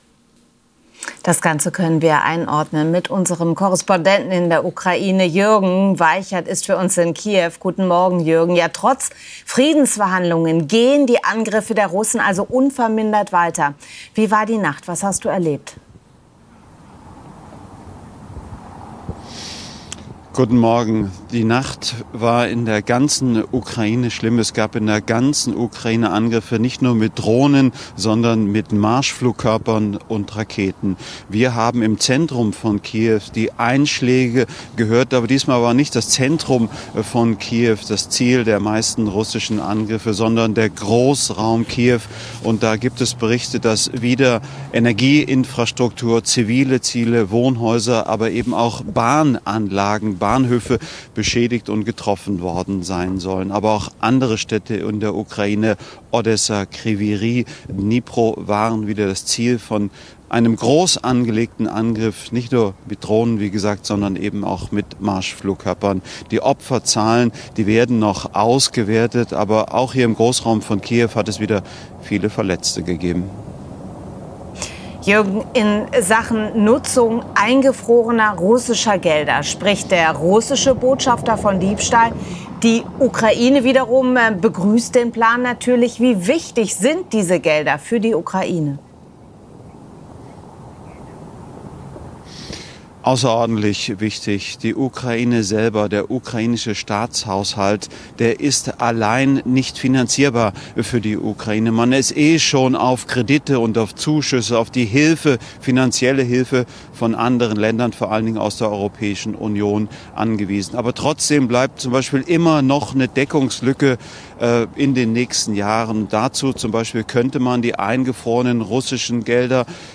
berichtet aus Kiew